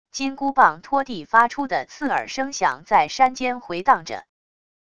金箍棒拖地发出的刺耳声响在山间回荡着wav音频